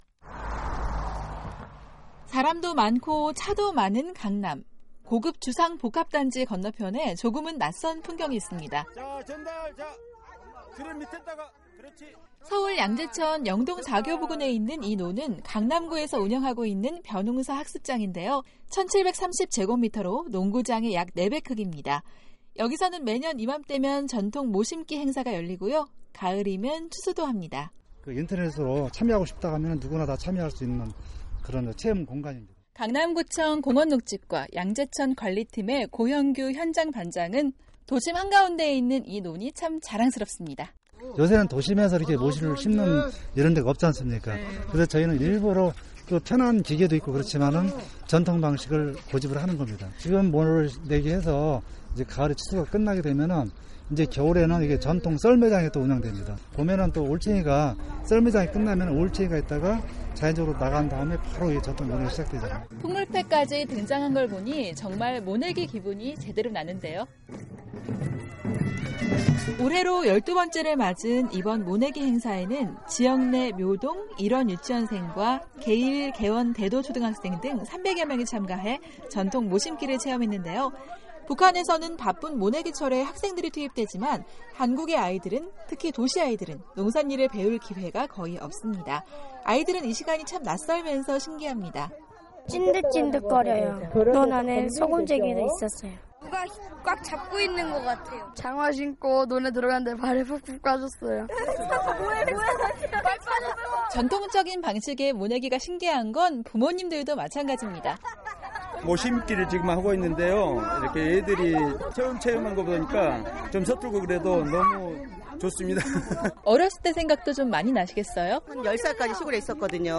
오늘은 서울 양재천에서 열린 모내기 체험현장을 소개합니다.